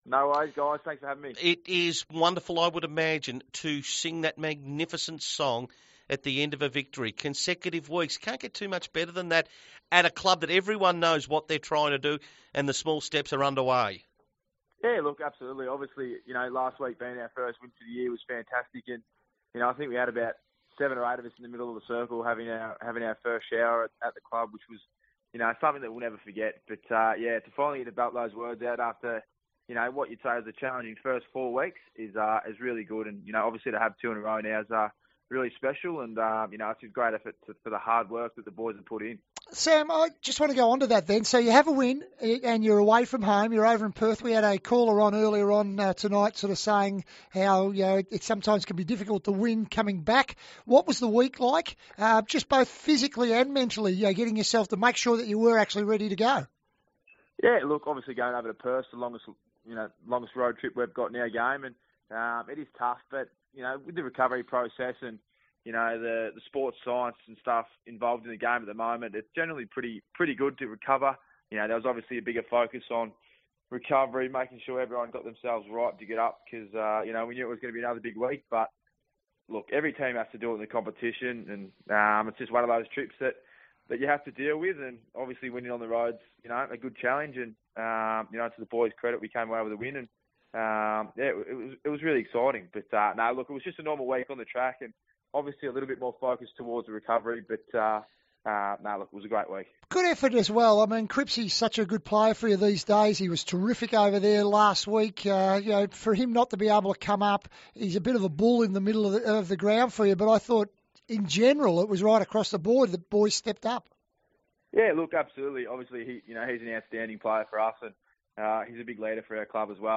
catches up with Sportsday Radio after the Blues' Round 6 victory over Essendon.